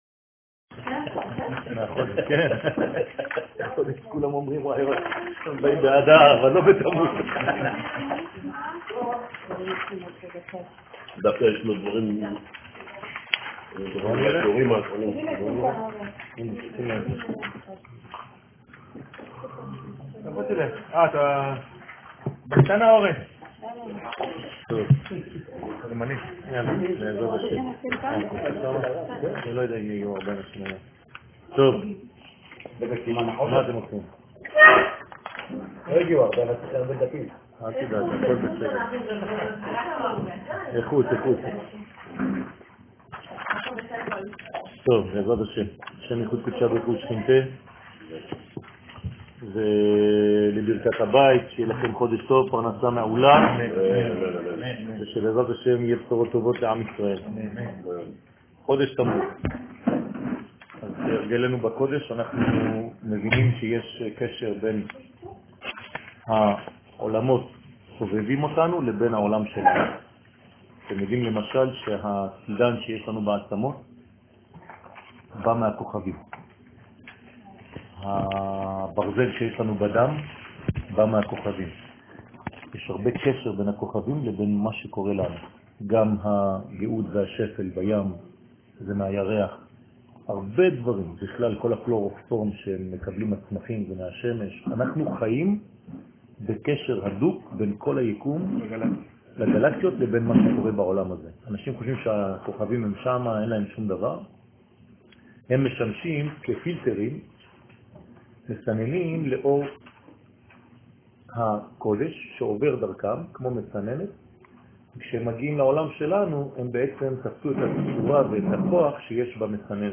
שיעור ראש חודש תמוז
שיעור-ראש-חודש-תמוז.m4a